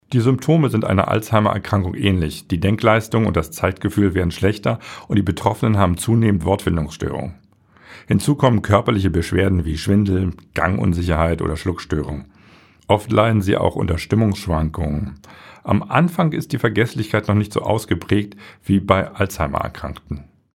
O-Töne04.09.2024